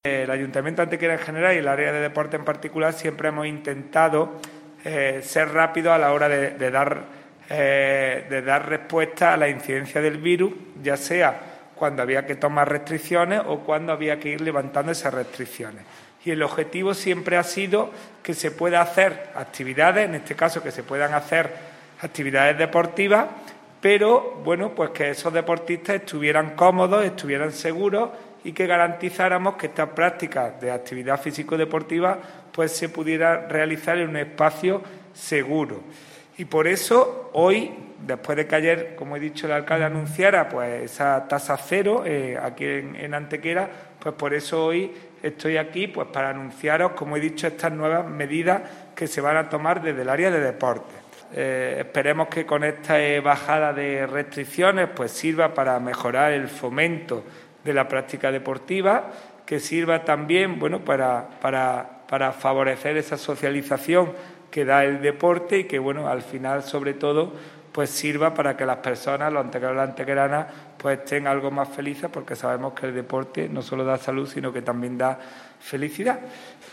El teniente de alcalde delegado de Deportes del Ayuntamiento de Antequera, Juan Rosas, ha confirmado en rueda de prensa la entrada en vigor –a partir de este lunes 18 de octubre– de nuevas normas menos restrictivas en el uso y participación en actividades deportivas municipales que se celebran en recintos como el Pabellón Fernando Argüelles o la Piscina Cubierta Municipal, así como la puesta en disposición para su reserva y alquiler de varias pistas deportivas destinadas a la práctica de deportes en equipo.
Cortes de voz